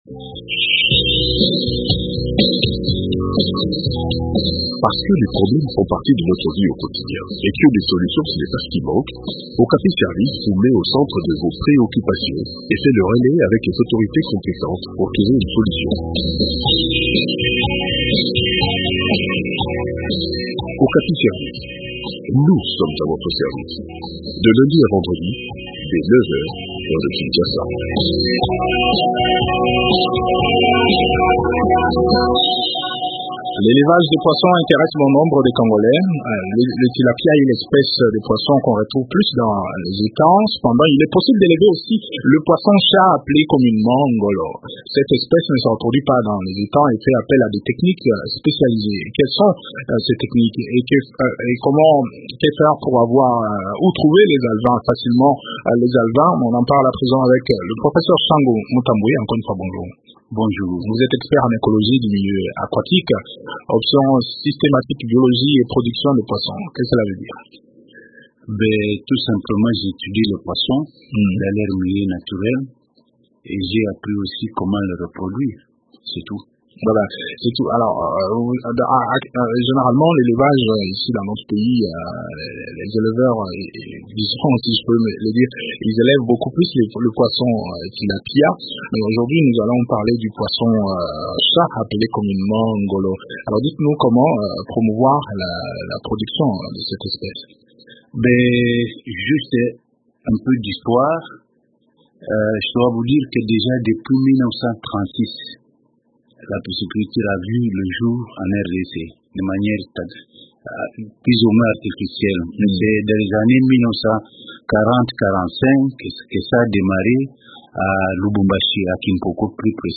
expert en écologie du milieu aquatique, option systématique, biologie et production de poisson